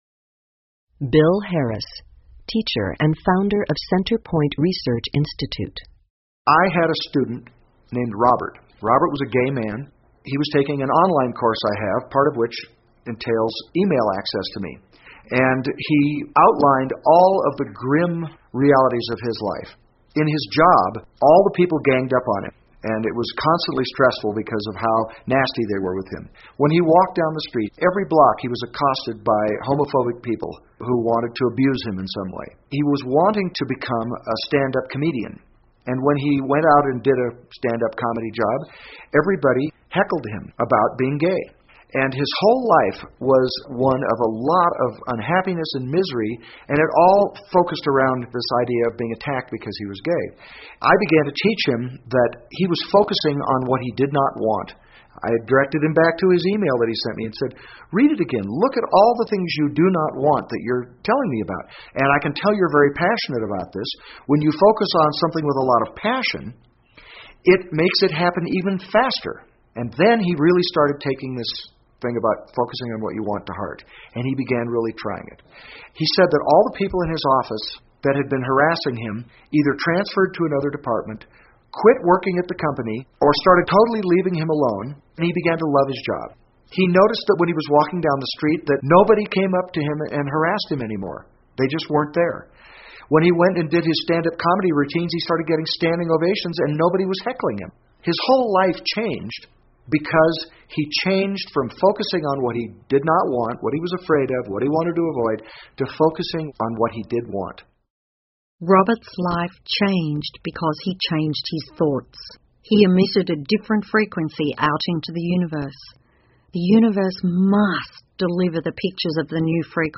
有声畅销书-秘密 1.11 听力文件下载—在线英语听力室